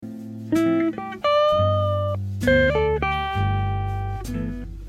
L’appogiature est ici un effet dynamique pour appuyer une note importante de la Tonalité (C Maj)